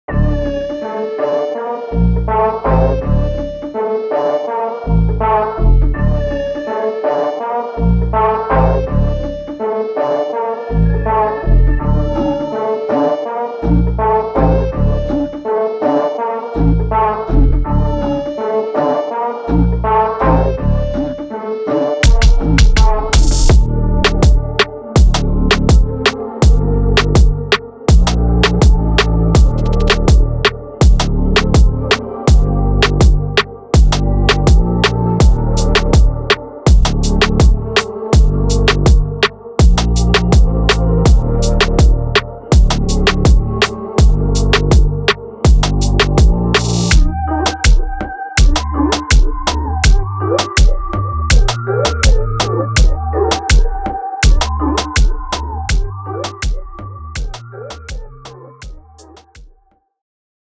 Reggaeton
old DMX drum machine samples